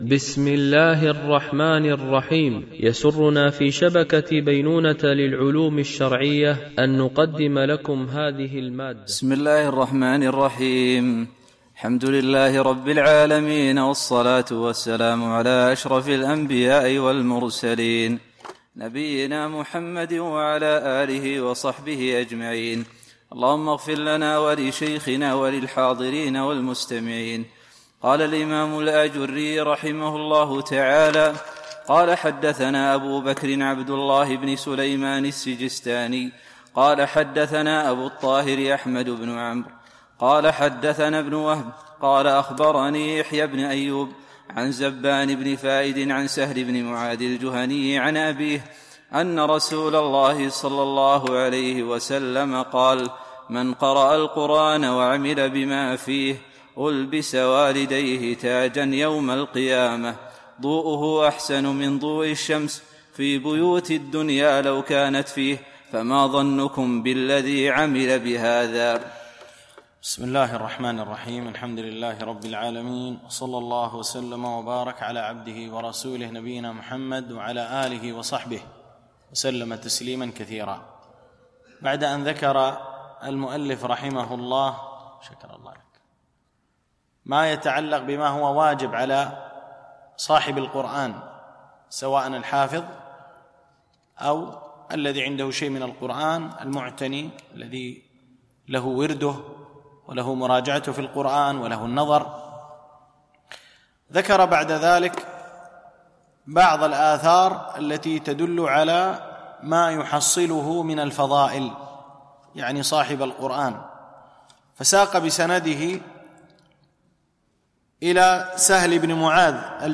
شرح آداب حملة القرآن ـ الدرس 5